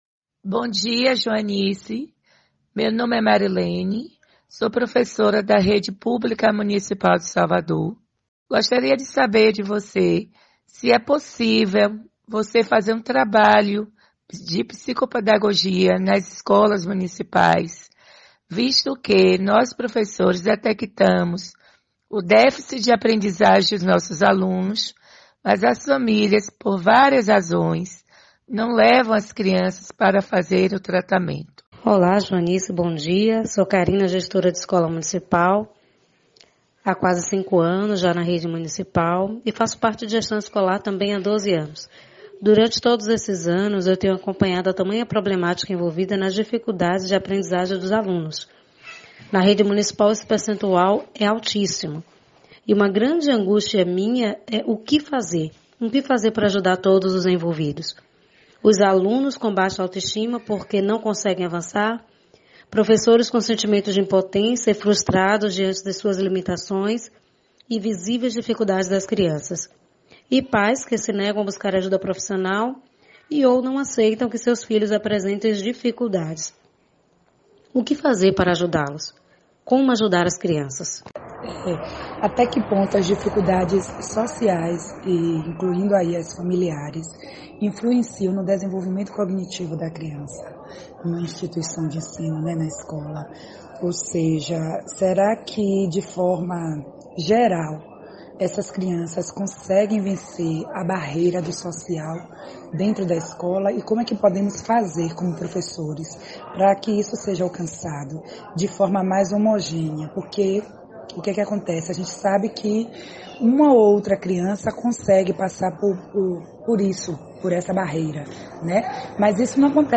OUÇA O ÁUDIO COM AS PERGUNTAS DE PROFESSORES: